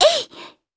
Yuria-Vox_Attack2_jp.wav